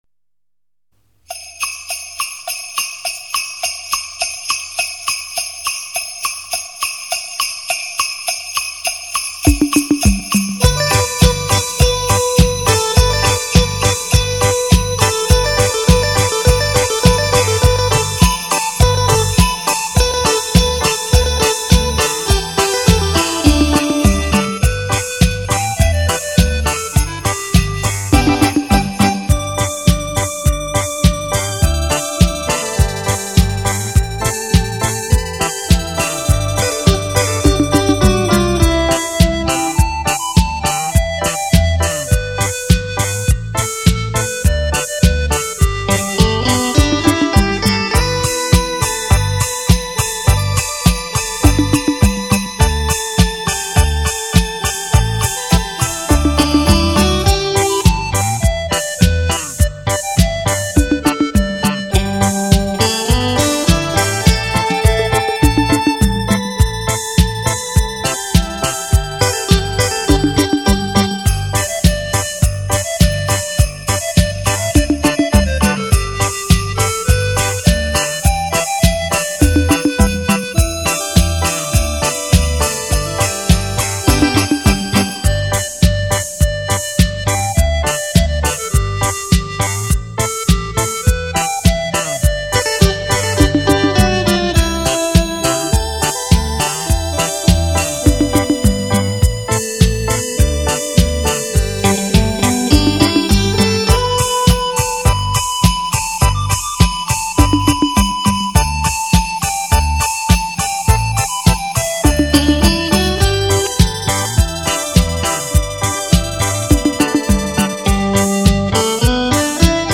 HiFi立体音响 2
360°环绕全场 小心你的喇叭！！